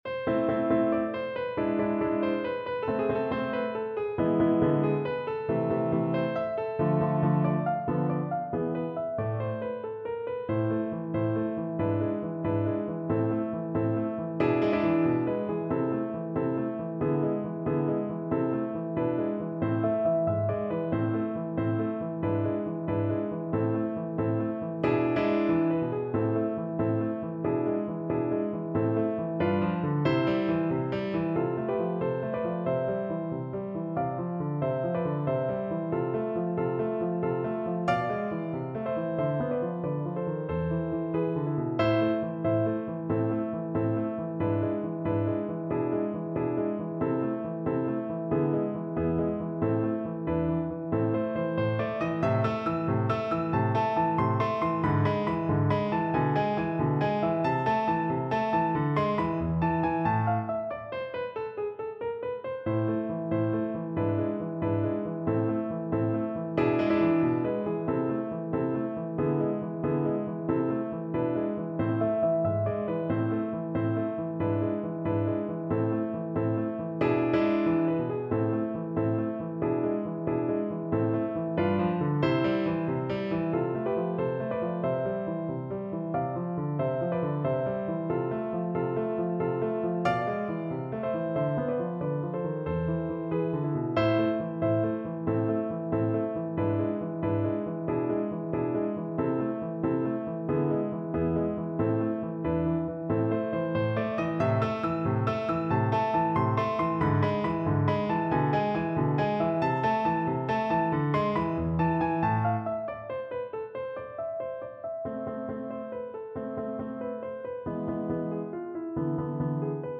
Play (or use space bar on your keyboard) Pause Music Playalong - Piano Accompaniment Playalong Band Accompaniment not yet available transpose reset tempo print settings full screen
Allegretto affettuoso =92
A minor (Sounding Pitch) E minor (French Horn in F) (View more A minor Music for French Horn )